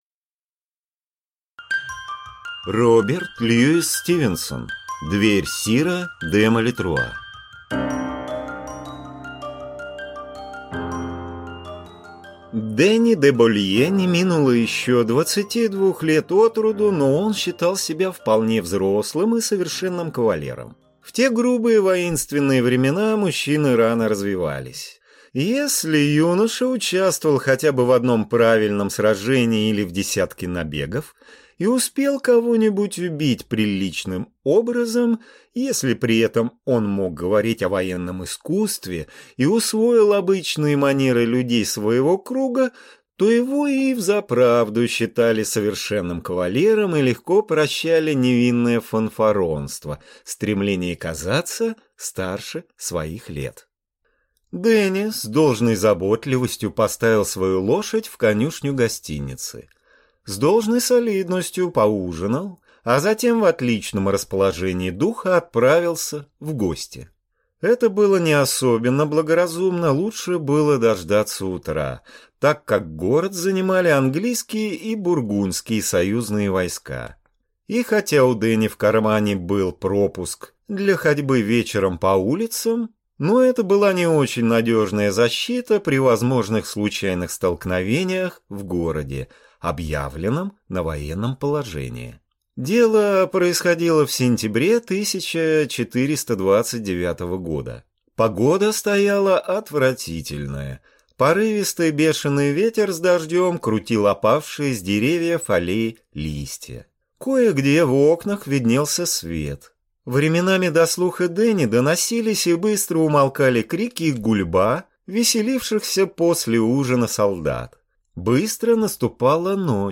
Аудиокнига Дверь сира де Малетруа | Библиотека аудиокниг